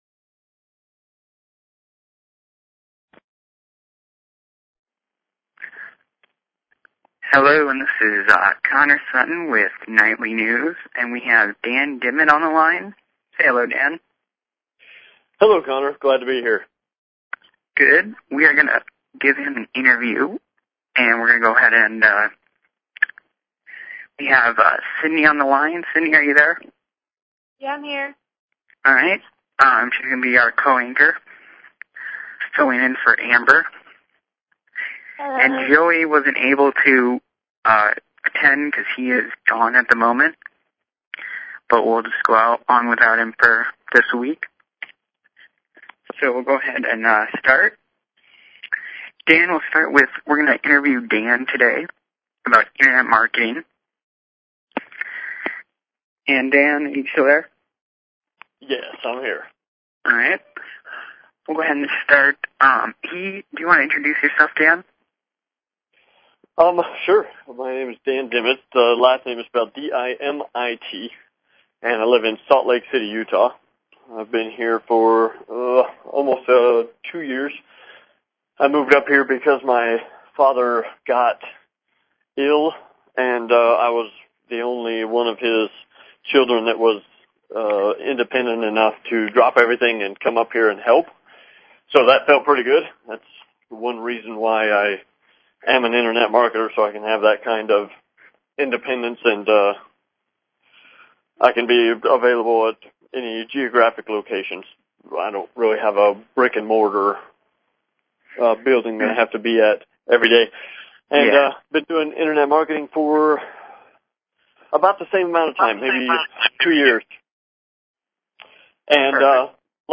Talk Show Episode, Audio Podcast, The_Nightly_News and Courtesy of BBS Radio on , show guests , about , categorized as